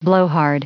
Prononciation du mot blowhard en anglais (fichier audio)
Prononciation du mot : blowhard